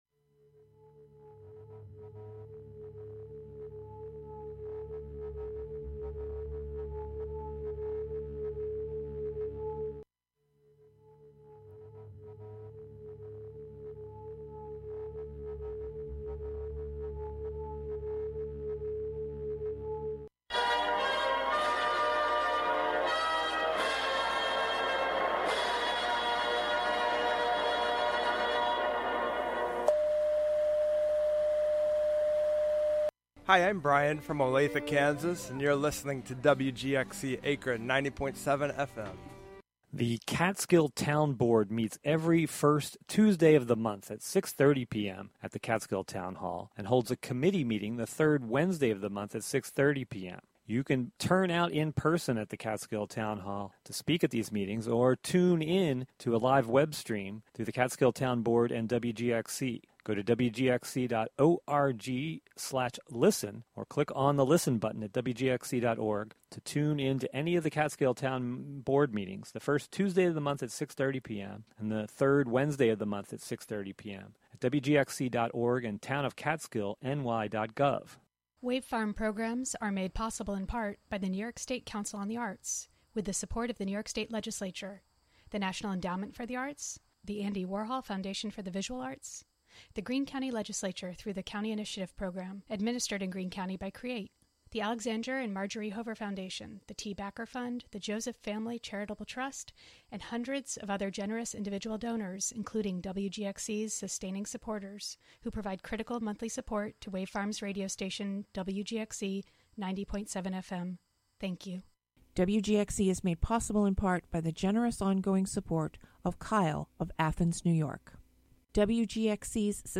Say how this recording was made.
Sometimes national, state, or local press conferences, meetings, or events are also broadcast live here.